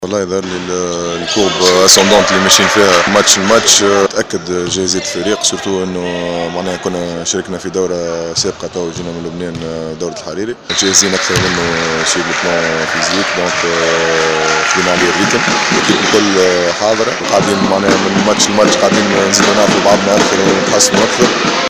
لاعب النجم الساحلي